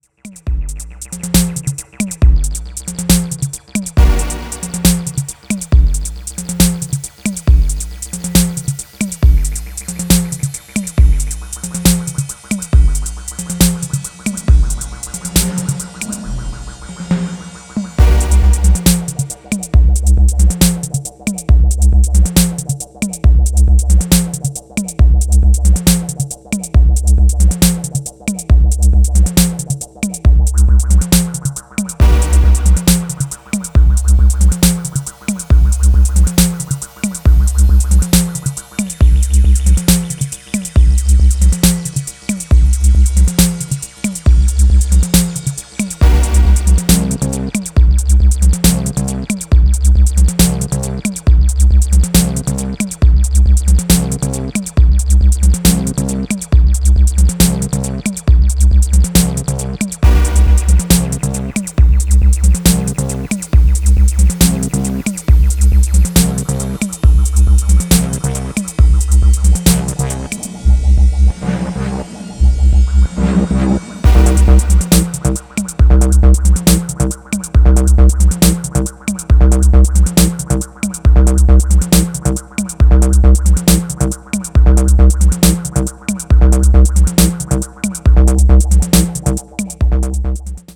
Styl: Dub/Dubstep, Breaks/Breakbeat